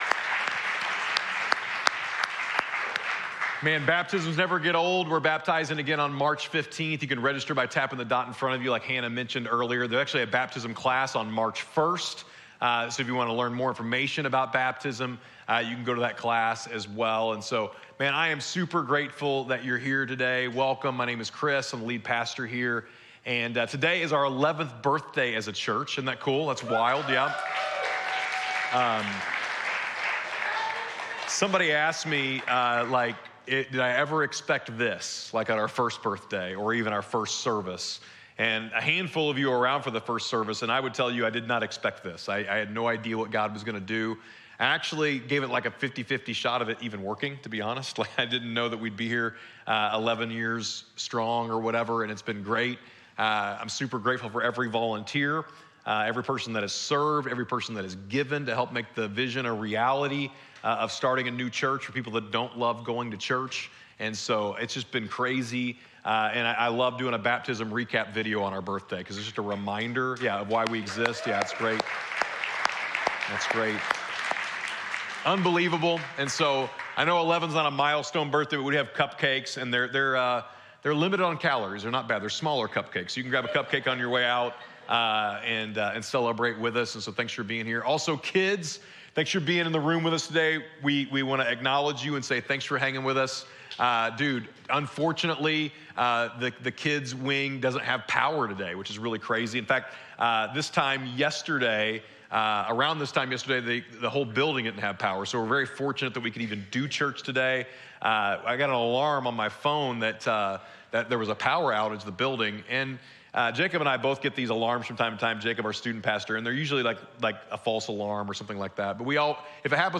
In the first message of Comeback Stories, we explore how God took Moses from failure and obscurity to purpose and influence.